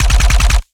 GUNAuto_RPU1 C Burst_04_SFRMS_SCIWPNS.wav